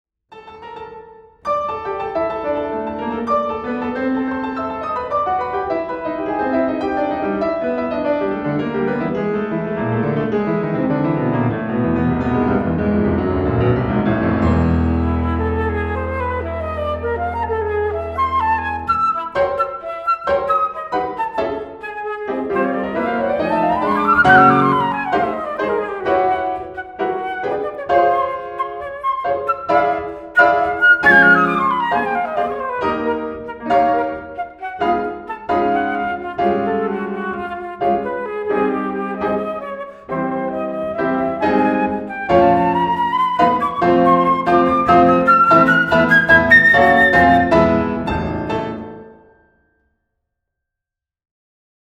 contemporary Australian Flute Music
Australian, Classical